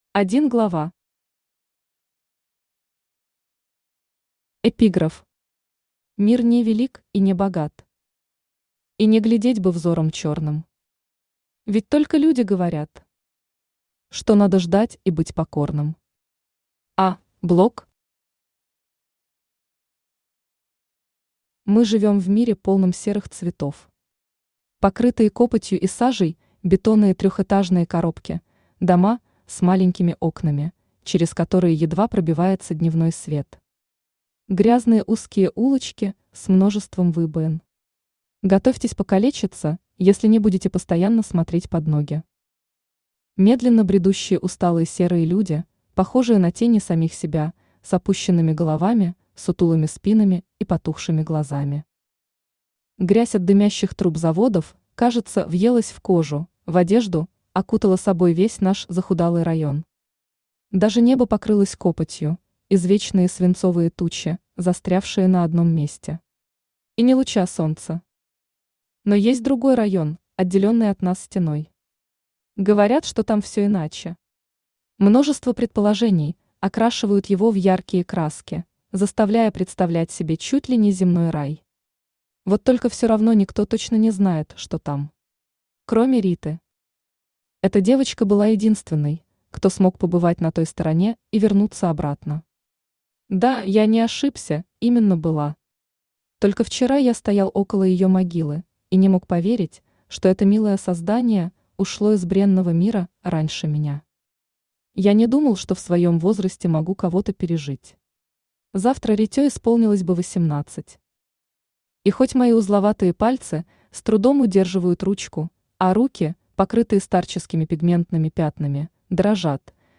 Аудиокнига За стеной серого мира | Библиотека аудиокниг
Aудиокнига За стеной серого мира Автор Екатерина Козина Читает аудиокнигу Авточтец ЛитРес.